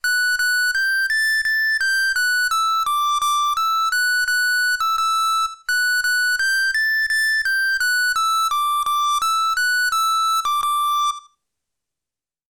【効果音】石油ファンヒーターの灯油切れチャイム - ポケットサウンド - フリー効果音素材・BGMダウンロード
石油ファンヒーター稼働中、灯油が少なくなった事をお知らせするチャイムの効果音素材です。